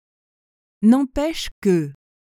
🎧 N’empêche que pronunciation
n‿ɑ̃pɛʃ kə/, which sounds like nah(n)-pesh kuh.